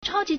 超级 (超級) chāojí
chao1ji2.mp3